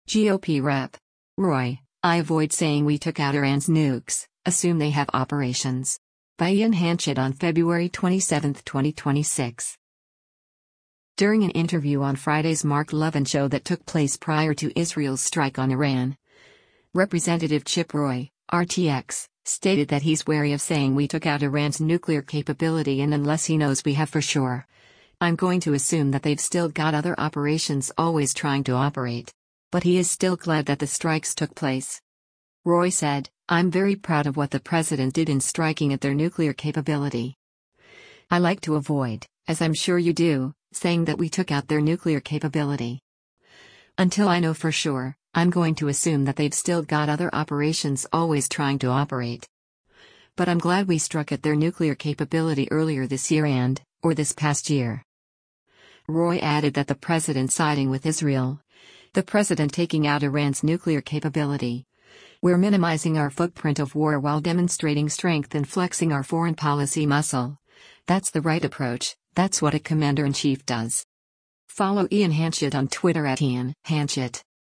During an interview on Friday’s “Mark Levin Show” that took place prior to Israel’s strike on Iran, Rep. Chip Roy (R-TX) stated that he’s wary of saying we took out Iran’s nuclear capability and unless he knows we have for sure, “I’m going to assume that they’ve still got other operations always trying to operate.” But he is still glad that the strikes took place.